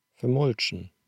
Utspraak op Platt: /fəɾmʊlʃn̩/